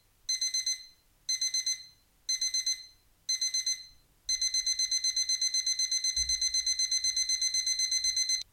Alarm Clock Digital